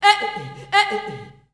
Index of /90_sSampleCDs/Voices_Of_Africa/SinglePhrasesFemale
19_E-e-o_RhytmVocalPerc.WAV